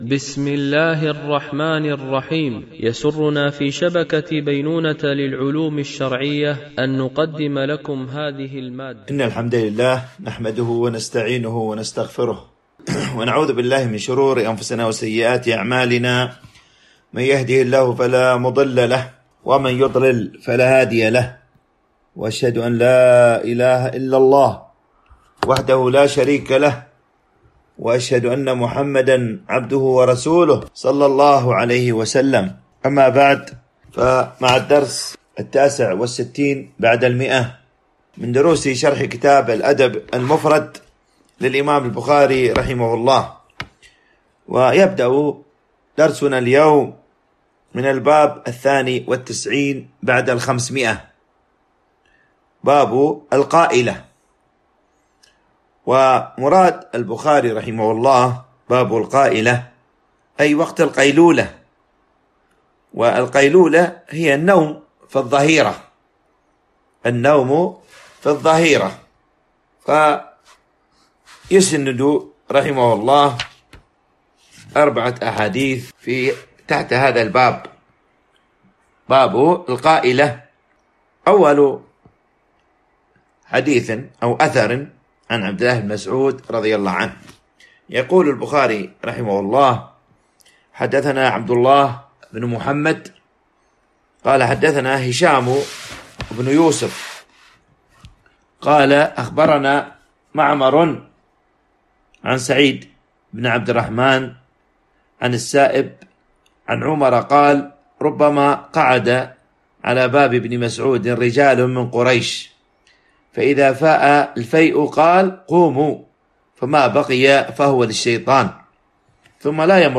شرح الأدب المفرد للبخاري ـ الدرس 169 ( الحديث 1238 - 1243 )